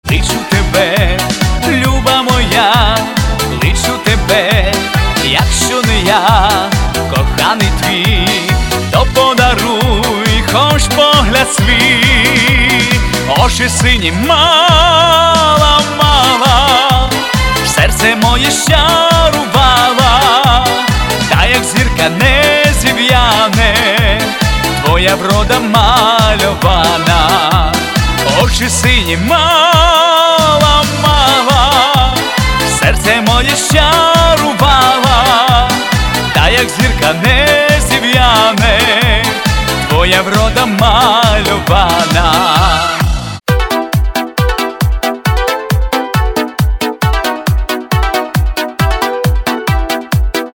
• Качество: 320, Stereo
шансон